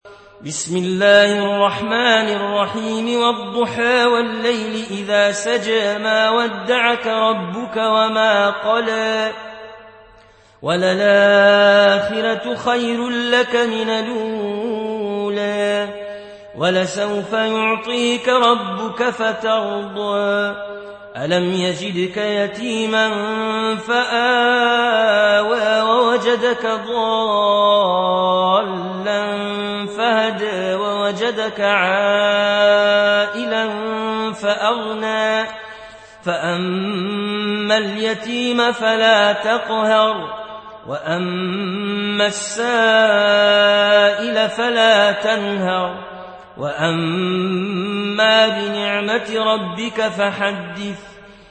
(رواية ورش)